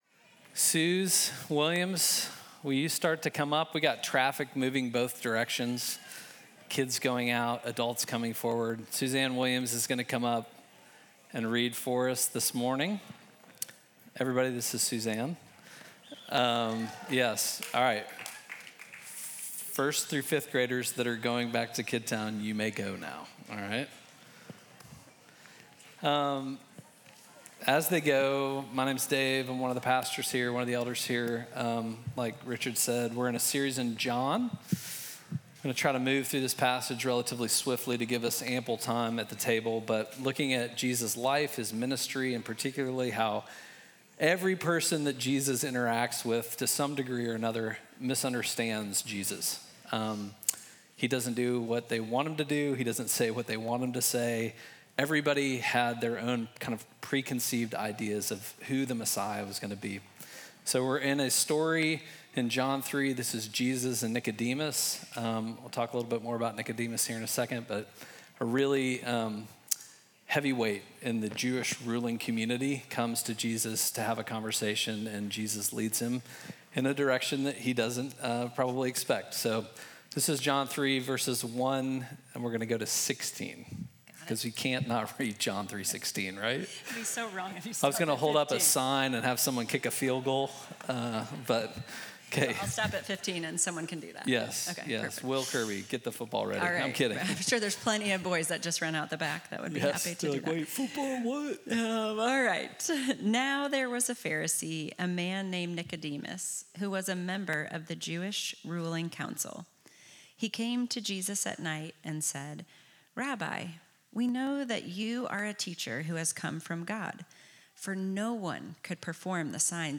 Midtown Fellowship Crieve Hall Sermons